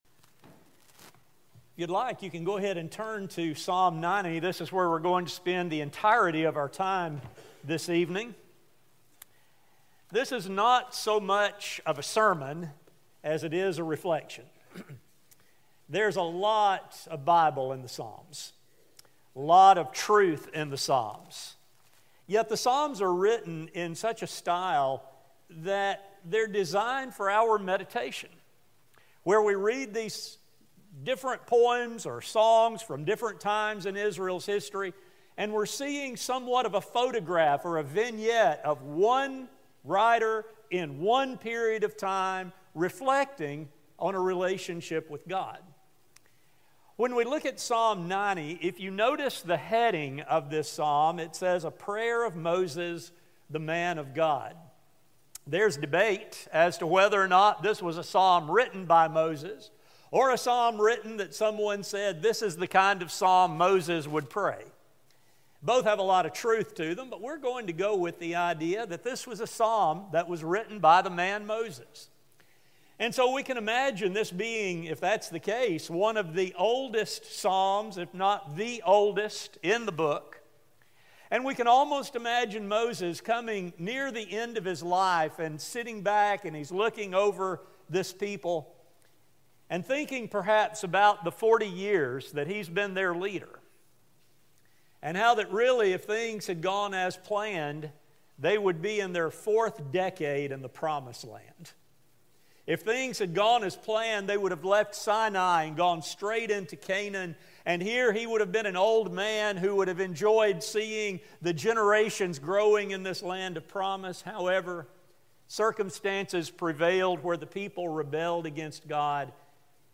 A sermon recording